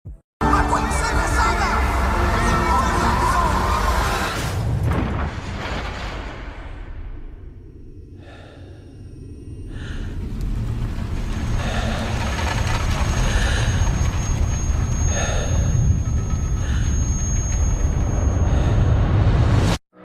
El choque de rayo mcqueen sound effects free download